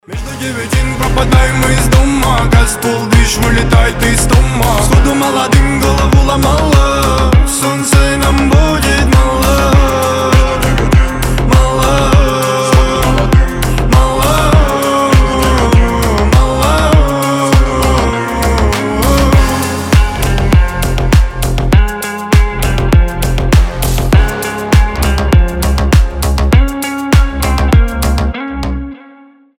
• Качество: 320, Stereo
гитара
пацанские
басы
качающие